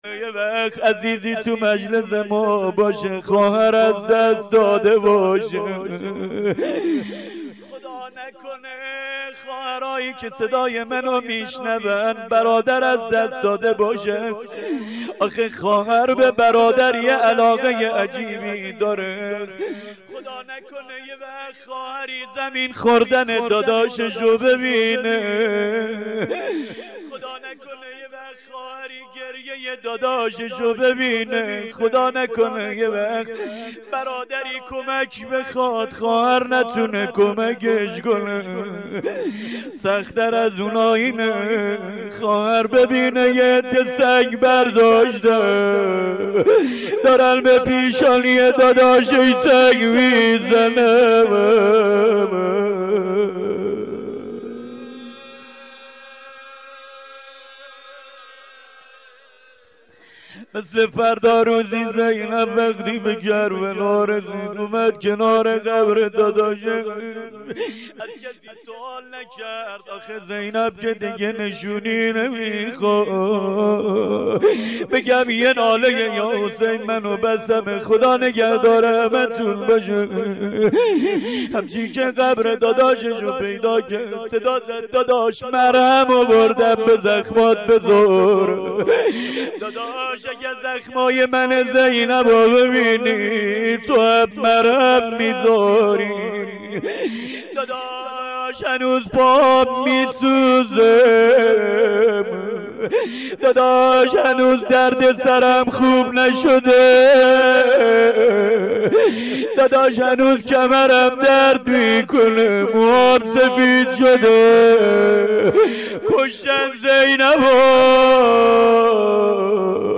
rozeh-payani.mp3